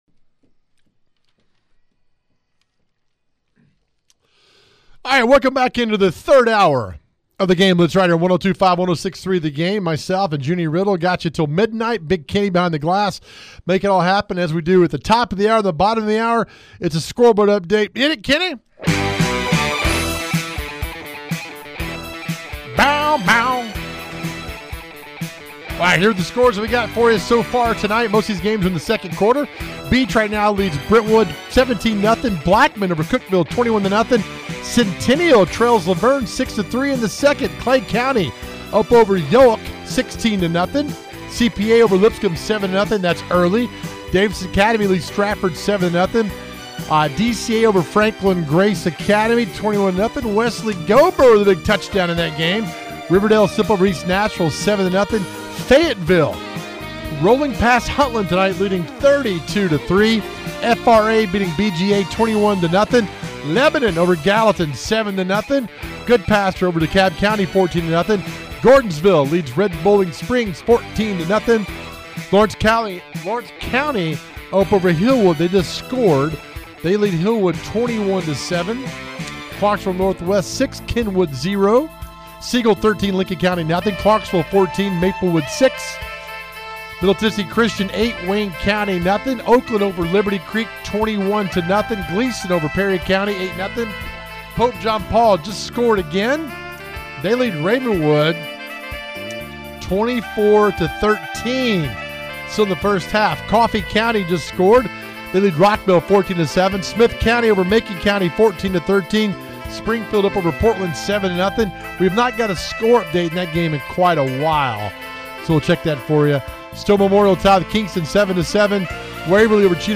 We have reporters on location along with coaches interviews!